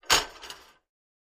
fo_fryingpan_rattle_01_hpx
Frying pans are slammed together. Slam, Frying Pan Rattle, Frying Pan Lid, Frying Pan